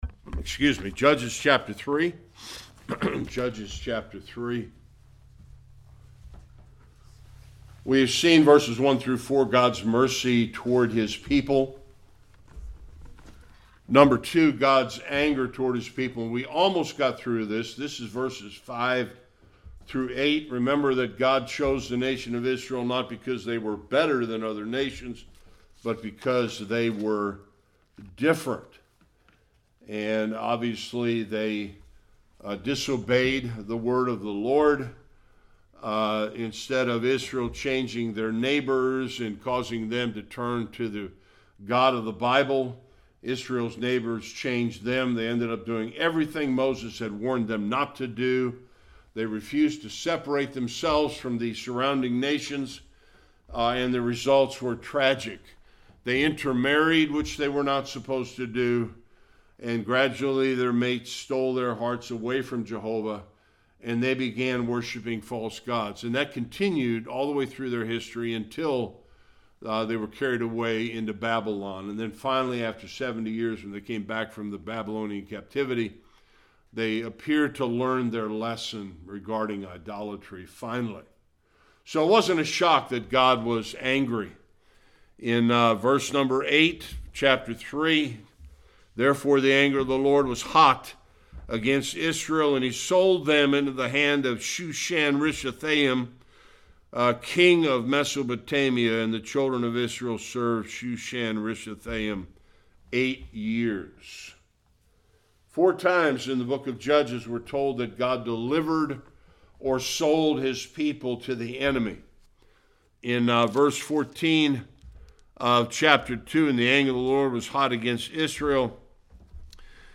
8-11 Service Type: Sunday School God raises up the first Judge to deliver Israel.